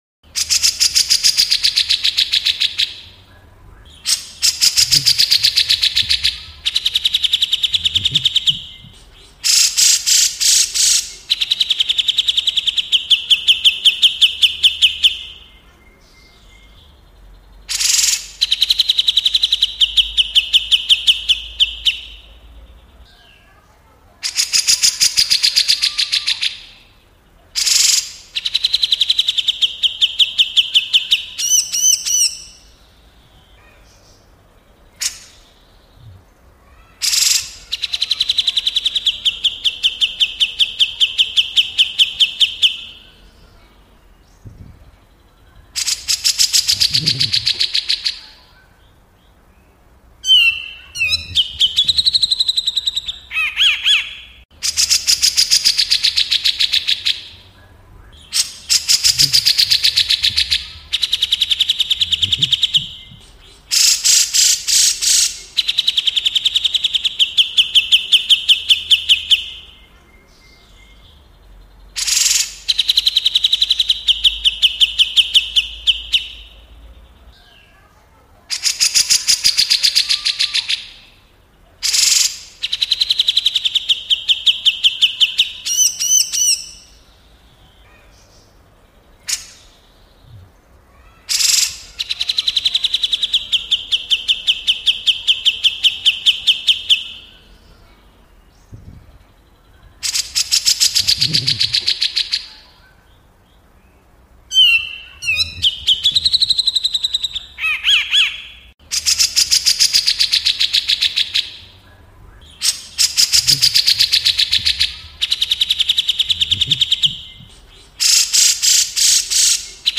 Download Suara Cucak Jenggot untuk Masteran Murai Batu dengan kualitas jernih dan durasi 1 jam nonstop. Suara ini cocok untuk melatih Murai Batu agar lebih gacor dan ngentrok.
Suara Cucak Jenggot Untuk Masteran Murai Batu
Tag: suara burung kecil suara Cucak Jenggot suara Kucica Hutan (Murai Batu) suara masteran burung
suara-burung-cucak-jenggot-untuk-masteran-murai-batu-id-www_tiengdong_com.mp3